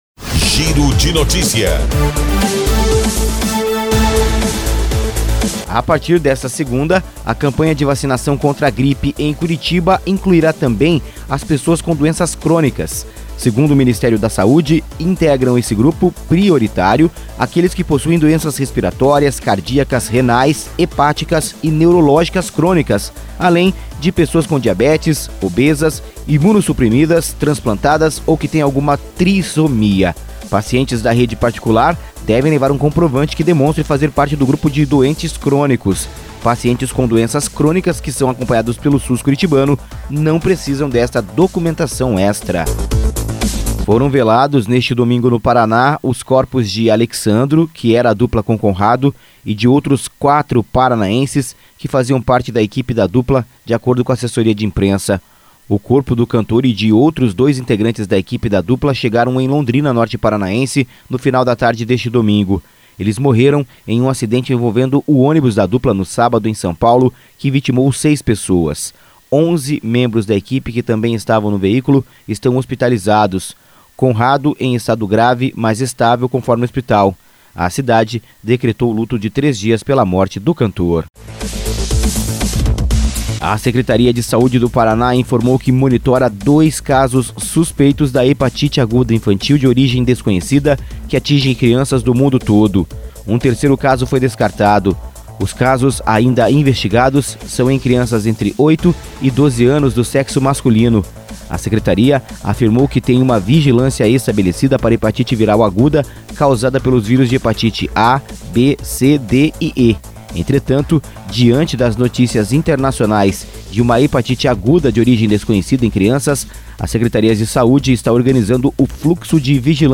Giro de Notícias – Edição da Manhã